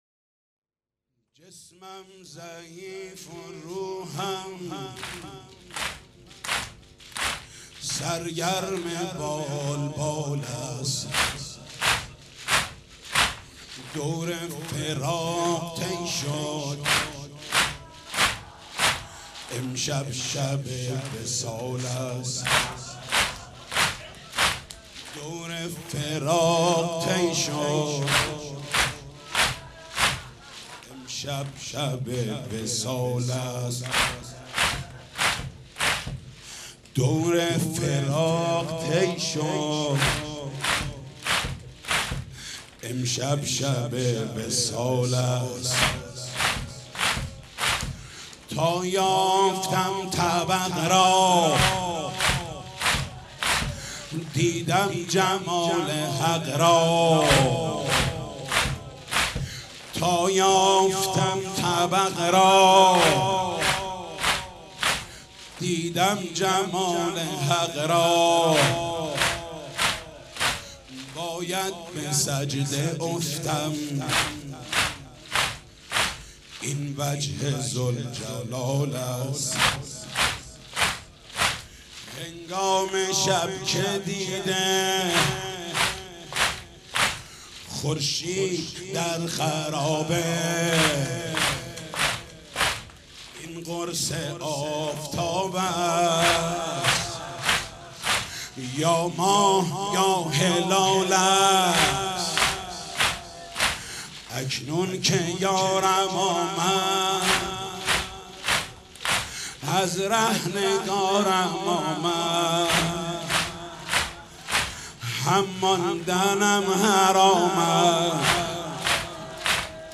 • سه ضرب بسیار زیبا
• محمود کریمی, نوحه محمود کریمی, شب سوم محرم 92 محمود کریمی, محرم 1435, محرم 92